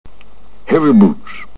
boots.wav